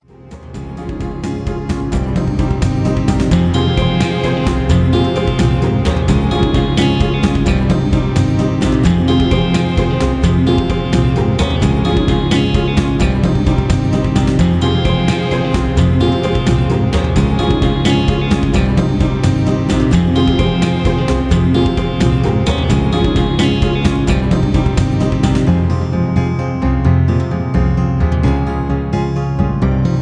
Royalty Free Music